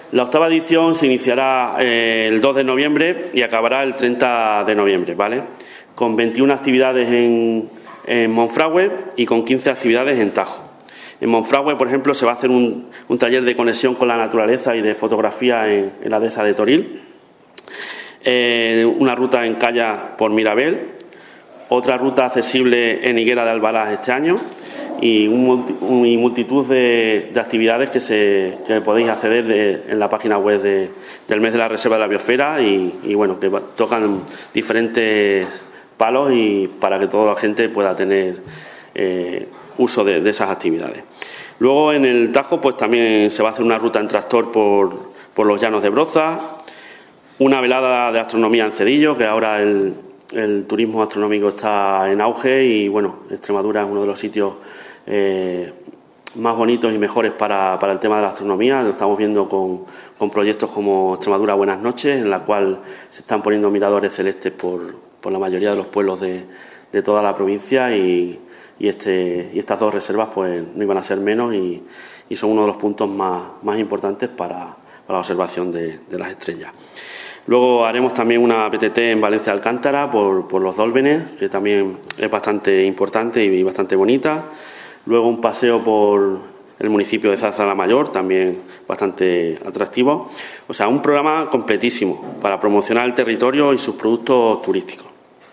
CORTES DE VOZ
Javier Díaz Cieza_Diputado de Reto Demográfico y Espacios Naturales Protegidos
Javier-Diaz-Cieza_Mes-Reserva-Biosfera-2024.mp3